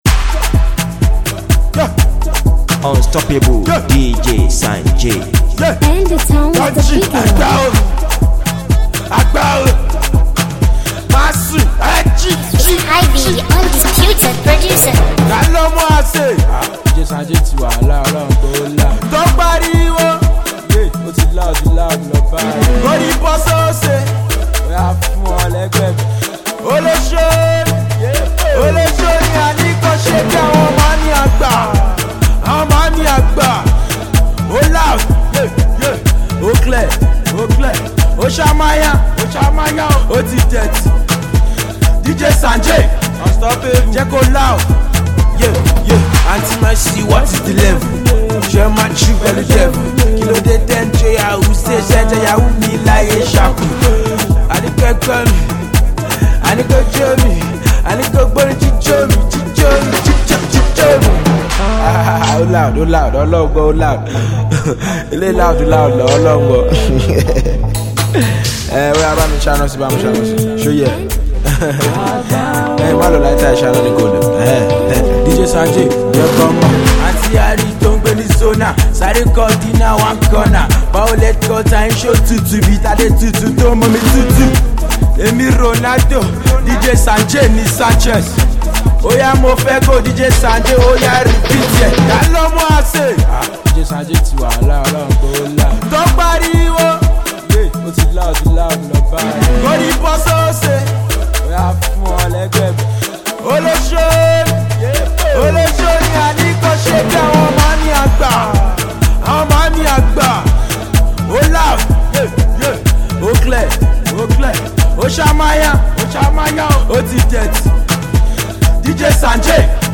Its a Shaku Shaku dance